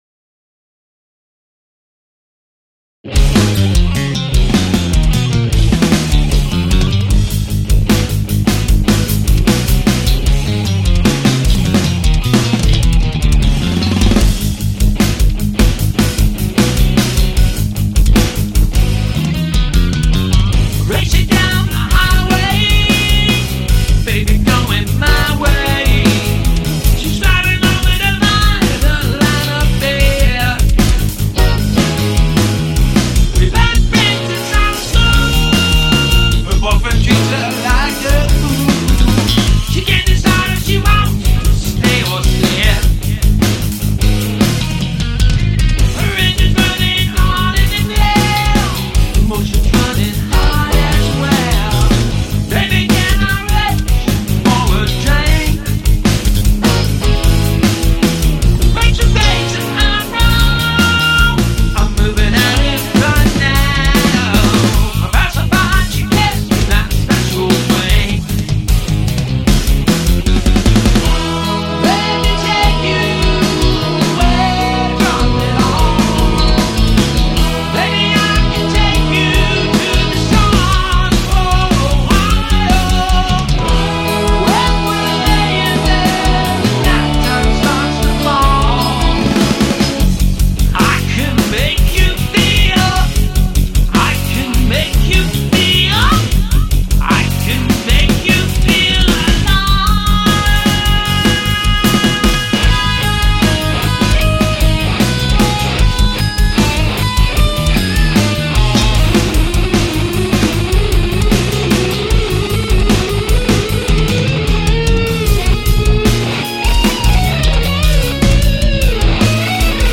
Please check out the Arena Rock song I am mixing for Client
Hard Rock, Prog Rock